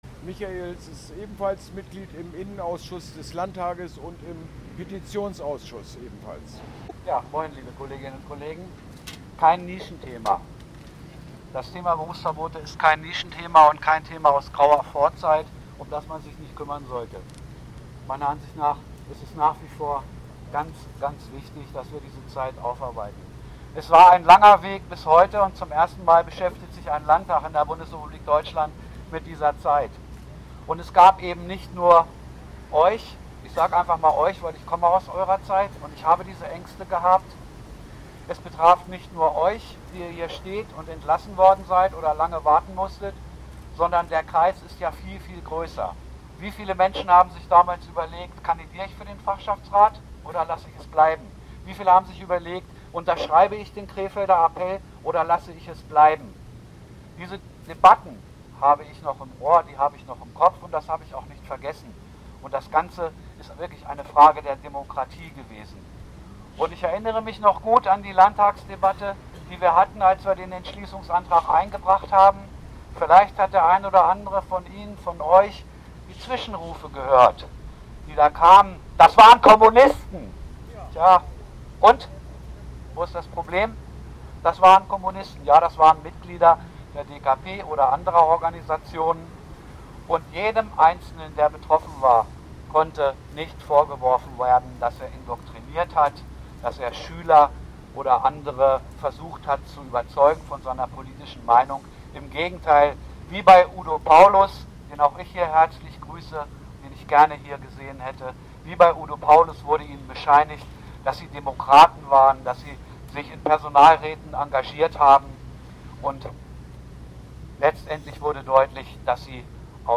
Kundgebung am Denkmal der wegen ihres Protests gegen Verfassungsbruch in früheren Zeiten mit Berufsverbot belegtenGöttinger Sieben“ vor dem Niedersächsischen Landtag.